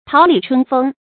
桃李春風 注音： ㄊㄠˊ ㄌㄧˇ ㄔㄨㄣ ㄈㄥ 讀音讀法： 意思解釋： 比喻學生受到良師的諄諄教誨。